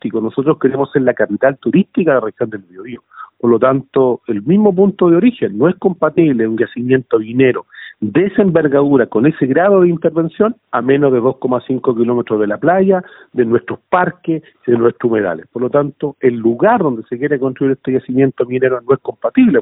Por su parte, el alcalde de Penco, Rodrigo Vera, valoró la visita a la comuna de los 12 embajadores de la Unión Europea, pero indicó que el objetivo de su administración es convertirse en la capital turística de la región del Bío Bío y eso no es compatible con un yacimiento minero de esa envergadura.
alcalde-penco.mp3